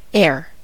air: Wikimedia Commons US English Pronunciations
En-us-air.WAV